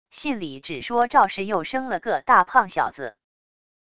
信里只说赵氏又生了个大胖小子wav音频生成系统WAV Audio Player